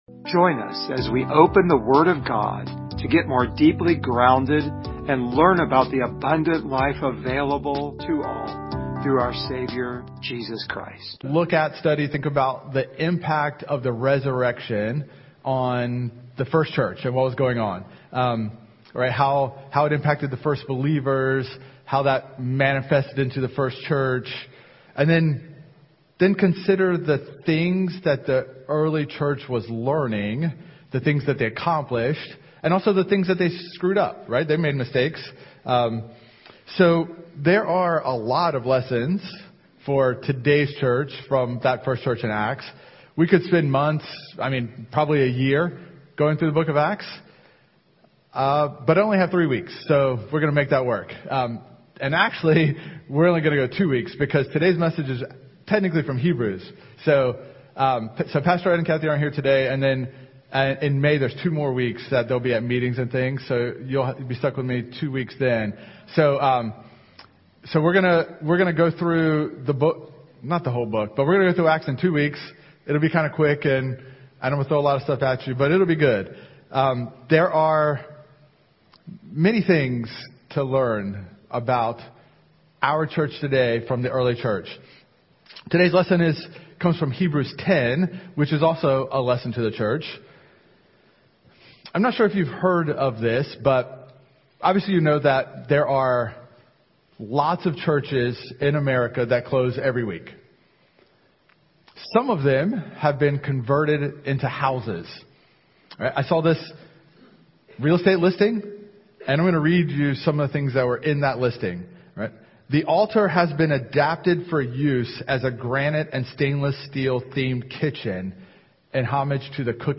Service Type: Sunday Morning
We are called to persevere in hope, trust in God’s faithfulness, and encourage one another in love as we remain grounded in faith, hope, and love. share this sermon « The Dream Team